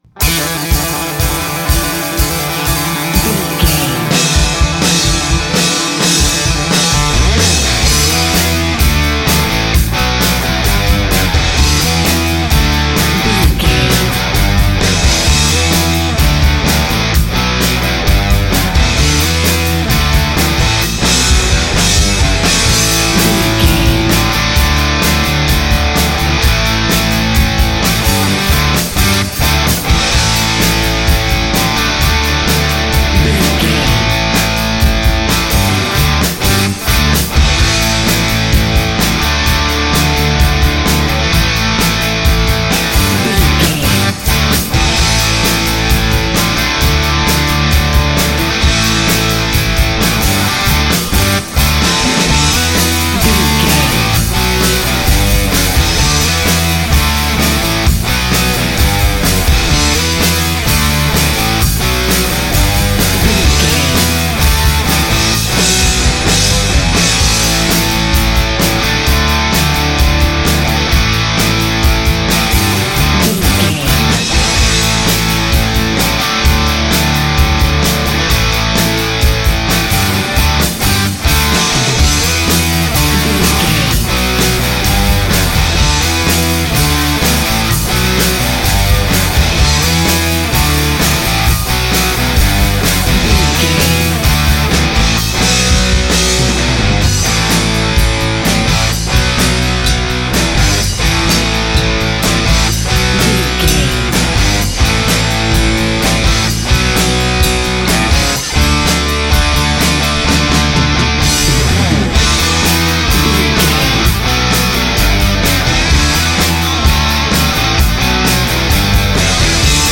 Epic / Action
Aeolian/Minor
drums
electric guitar
bass guitar
Sports Rock
hard rock
metal
lead guitar
aggressive
energetic
intense
powerful
nu metal
alternative metal